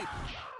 ninja sound effect 5
ninja-sound-effect-5